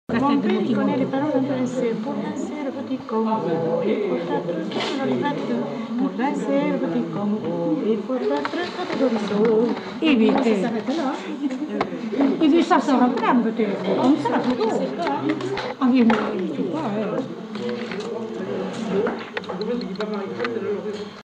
Genre : chant
Effectif : 1
Type de voix : voix de femme
Production du son : chanté
Danse : congo